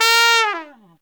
Index of /90_sSampleCDs/Best Service ProSamples vol.25 - Pop & Funk Brass [AKAI] 1CD/Partition C/TRUMPET FX3